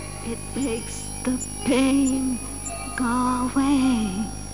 home *** CD-ROM | disk | FTP | other *** search / Horror Sensation / HORROR.iso / sounds / iff / painaway.snd ( .mp3 ) < prev next > Amiga 8-bit Sampled Voice | 1992-09-02 | 119KB | 1 channel | 26,128 sample rate | 4 seconds